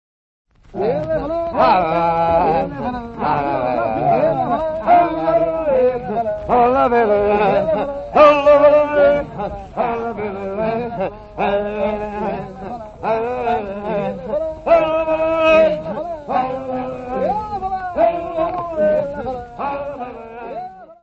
Notes:  Recolha de Michel Giacometti, pertencente aos arquivos do Ministério da Cultura; A ordem das faixas 16 e 17 está trocada no folheto (cf. as notas de conteúdo); Disponível na Biblioteca Municipal Orlando Ribeiro - Serviço de Fonoteca
Music Category/Genre:  World and Traditional Music